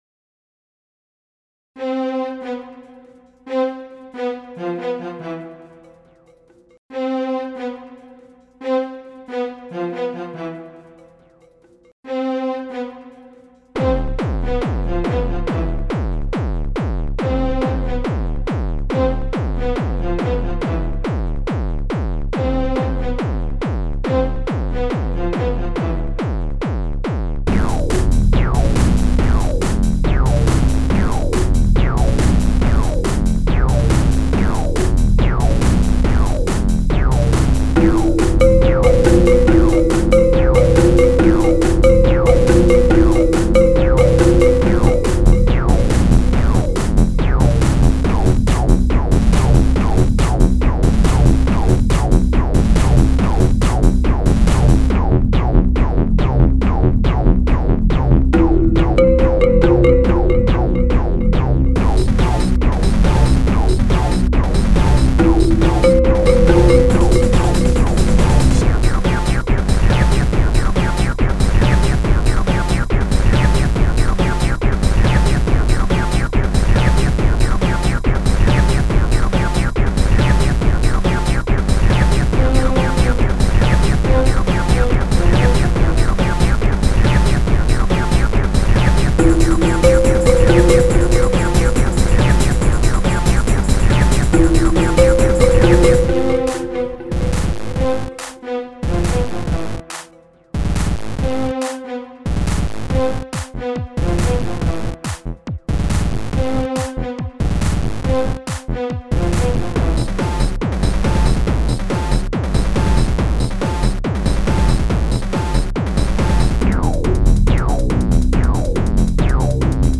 Goth-Industrial Dance